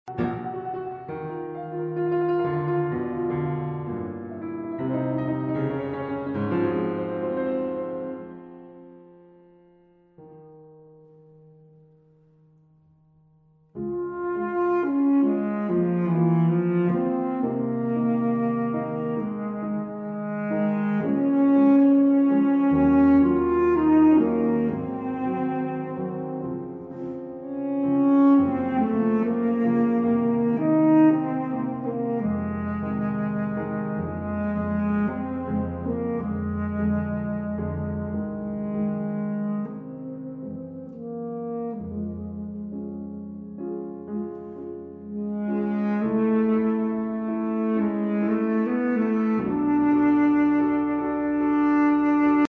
CD Musique Instrumentale